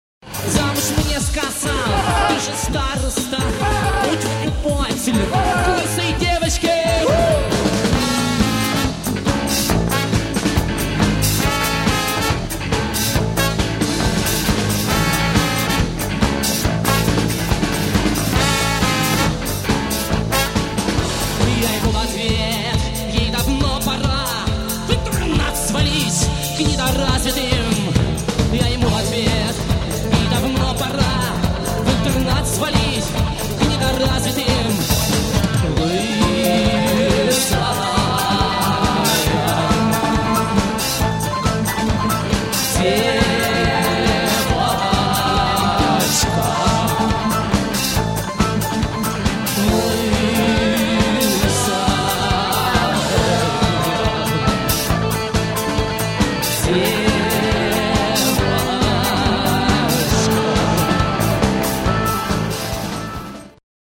AUDIO, stereo